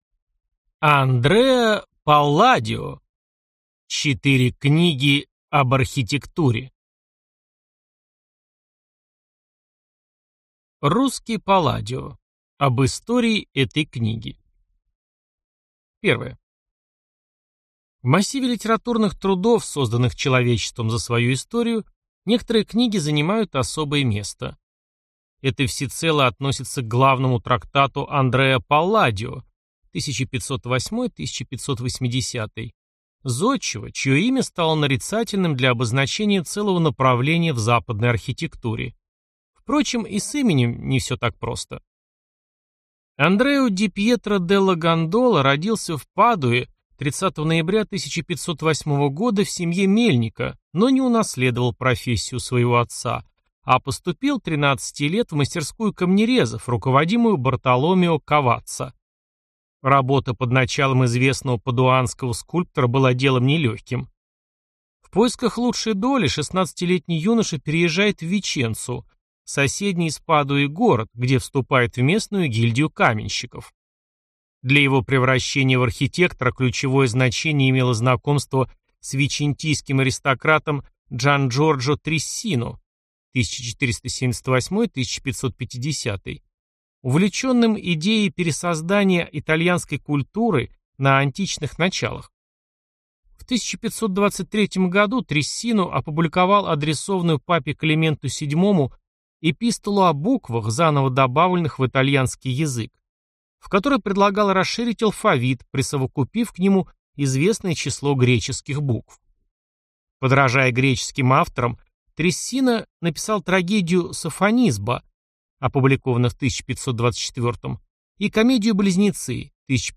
Аудиокнига Четыре книги об архитектуре | Библиотека аудиокниг